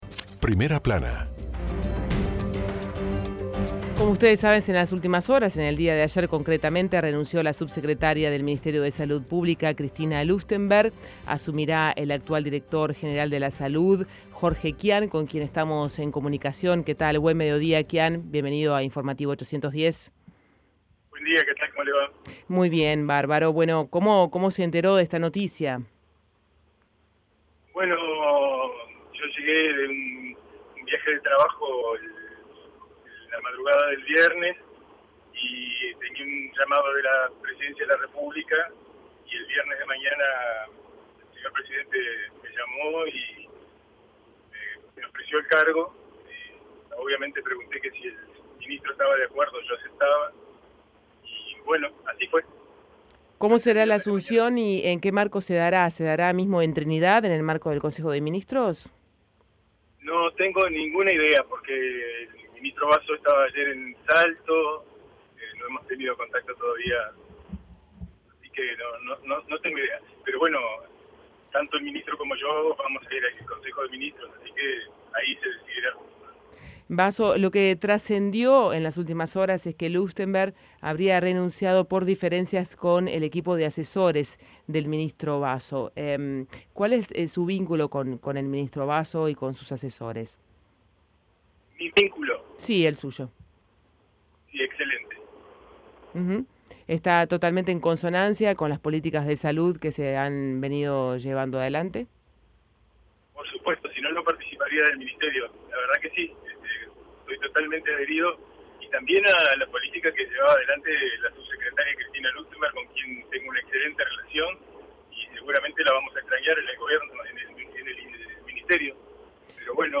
El Dr. Jorge Quian habló en Informativos 810 acerca de su nueva designación como subsecretario de Salud Pública y dijo que es una propuesta que le llena de orgullo y trabajo.
En una entrevista exclusiva